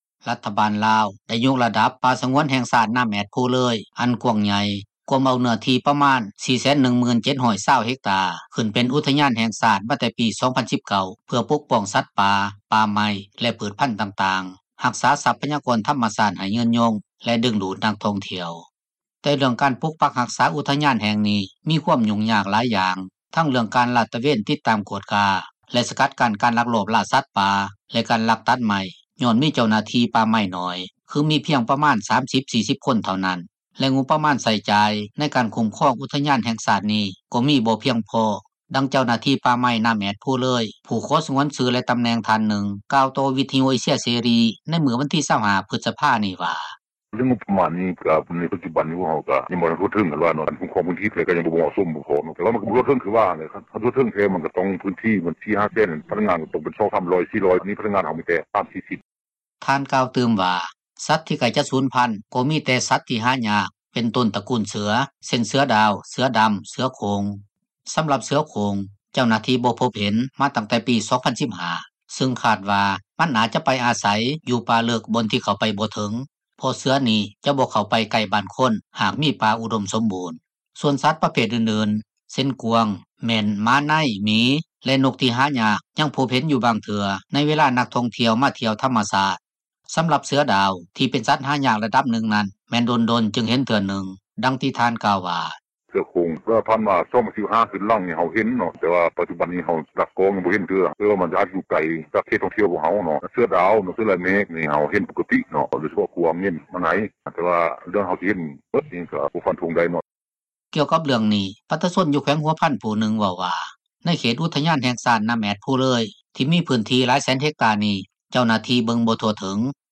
ດັ່ງເຈົ້າໜ້າທີ່ ປ່າໄມ້ນໍ້າແອດ-ພູເລີຍ ຜູ້ຂໍສງວນຊື່ ແລະຕໍາຕໍາແໜ່ງ ທ່ານນຶ່ງກ່າວຕໍ່ ວິທຍຸ ເອເຊັຽ ເສຣີ ໃນມື້ວັນທີ 25 ພຶສພານີ້ວ່າ: